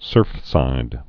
(sûrfsīd)